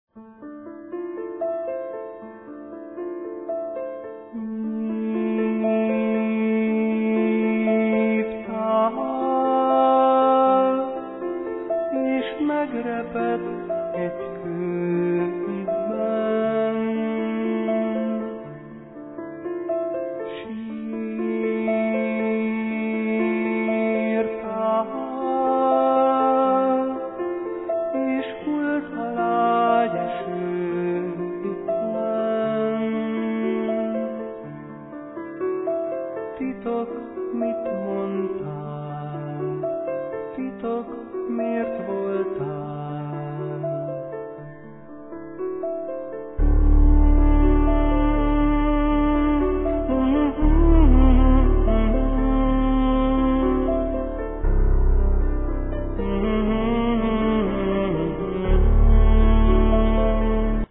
Piano, Vocal